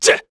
Lusikiel-Vox_Attack3_kr.wav